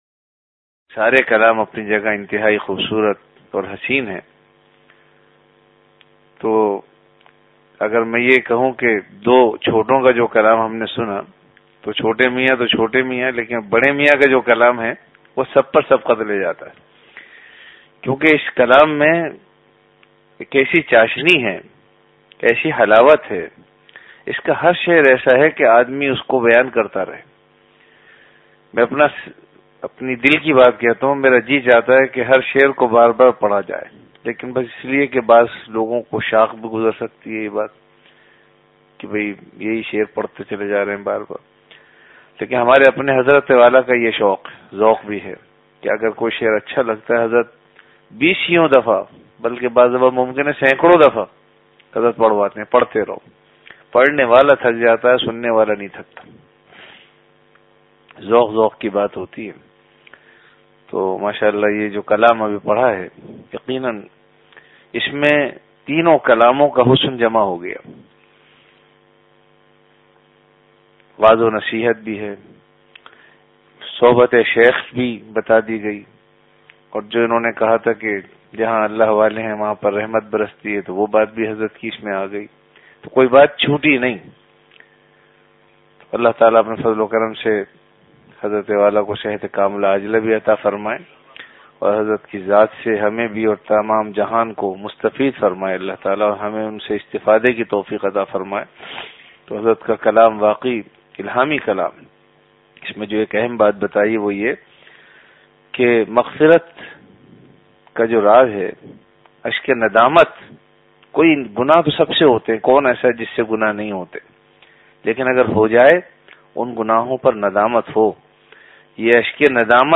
CategoryMajlis-e-Zikr
Event / TimeAfter Isha Prayer